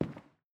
Footsteps / Carpet
Carpet-06.wav